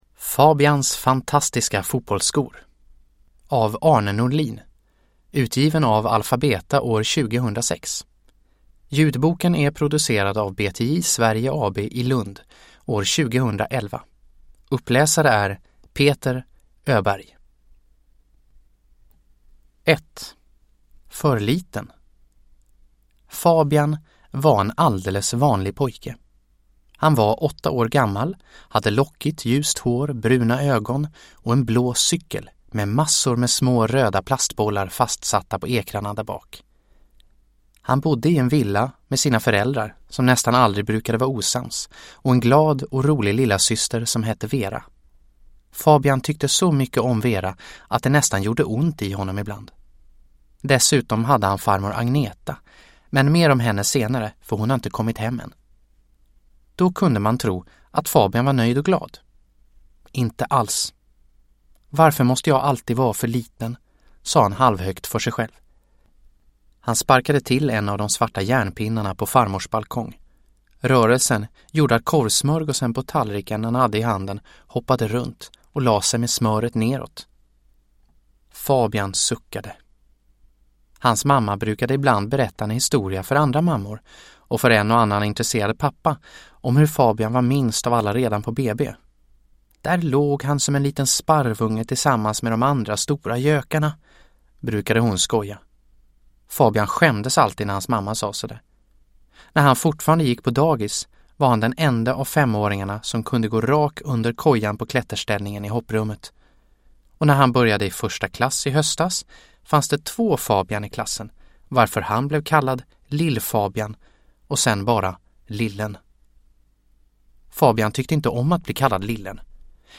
Fabians fantastiska fotbollsskor – Ljudbok – Laddas ner
Produkttyp: Digitala böcker